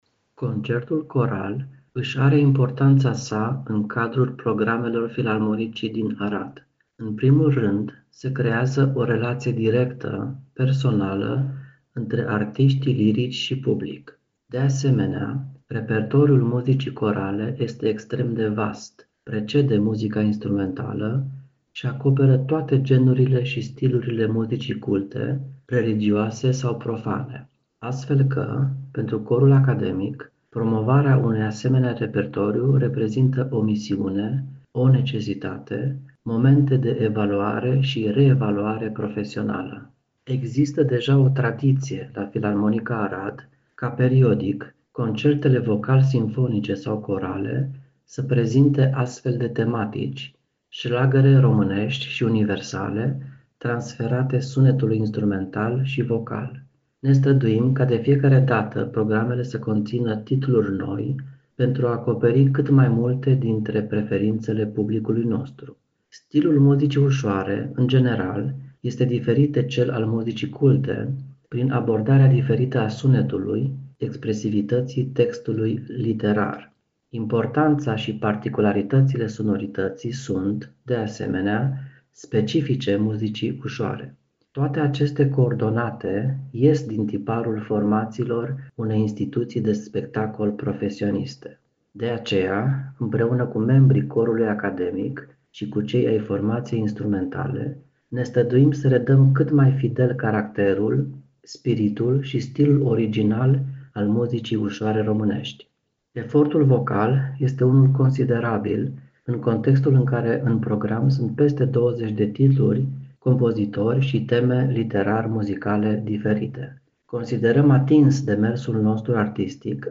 AUDIO / Interviu